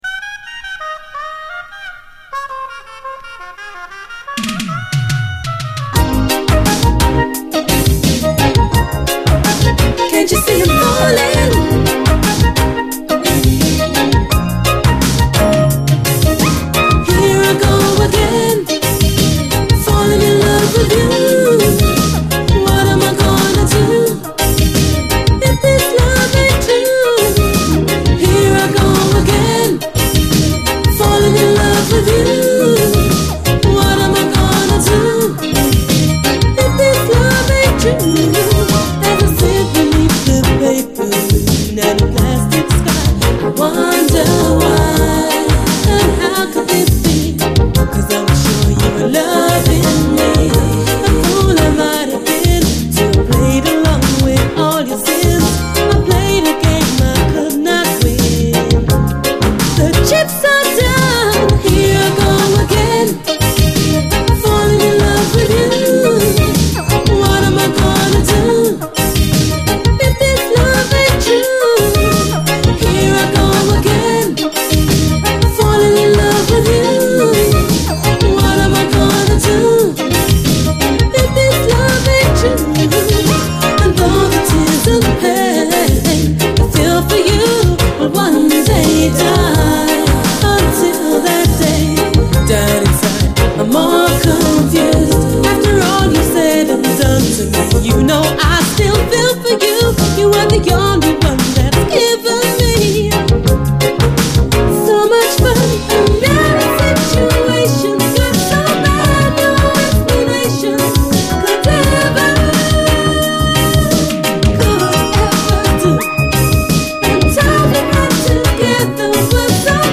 REGGAE, 7INCH
南国っぽいトロピカルで爽快なR&B〜UKソウル・アレンジがめちゃくちゃ気持ちいいキラー・チューン！